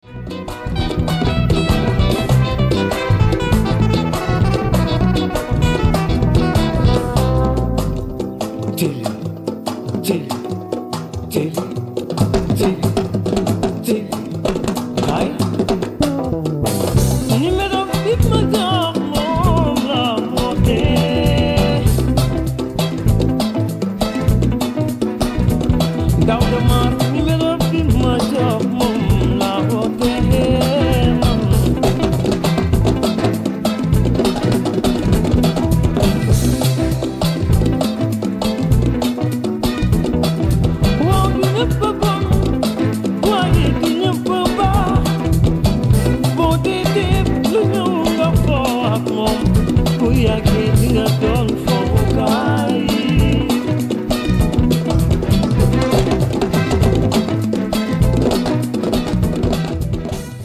keyboards